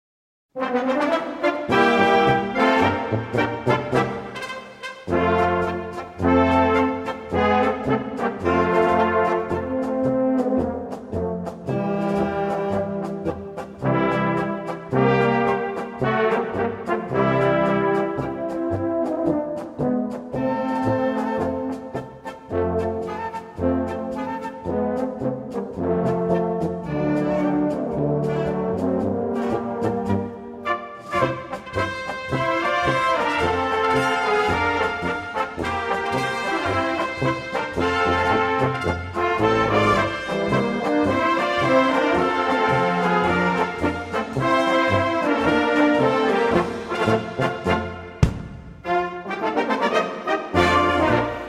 Gattung: Polka
Besetzung: Blasorchester
mit Gesangsstimme.